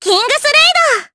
May-Vox_Kingsraid_jp.wav